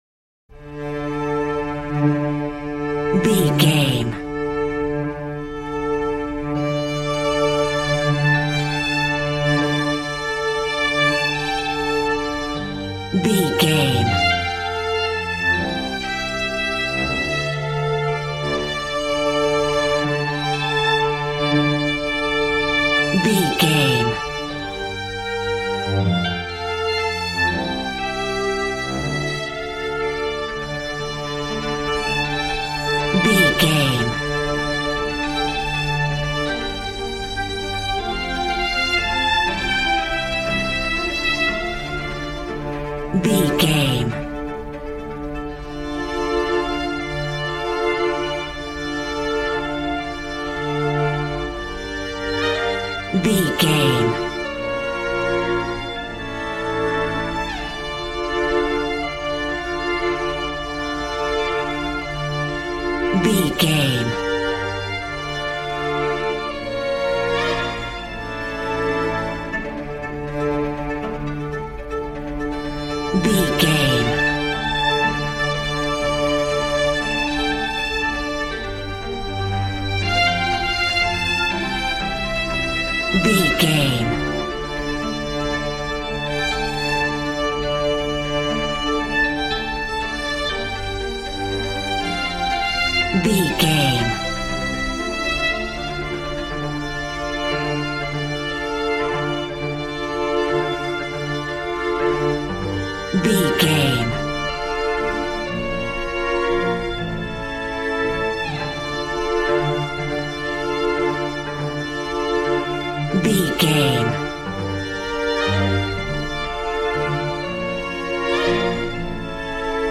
Modern film strings for romantic love themes.
Regal and romantic, a classy piece of classical music.
Aeolian/Minor
D
regal
cello
violin
brass